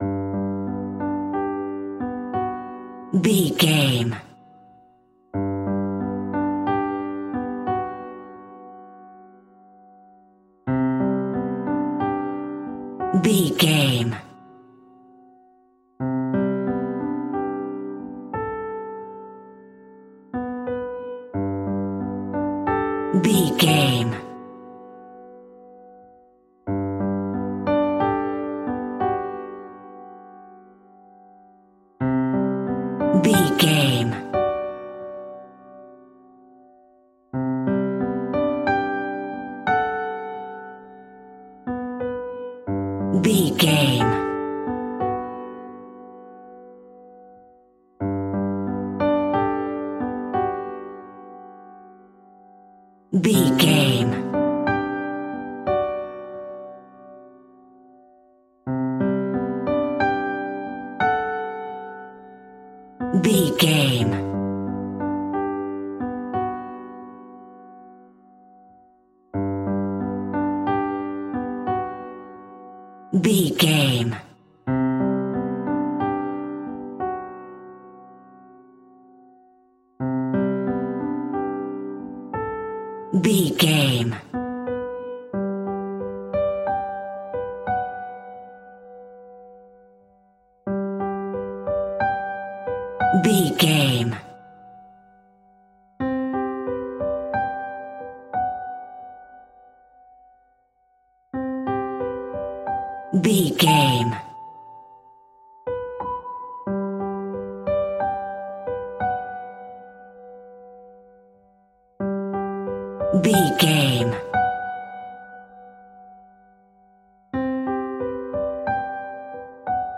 Soft airy and light classical piano music in a major key.
Regal and romantic, a classy piece of classical music.
Ionian/Major
romantic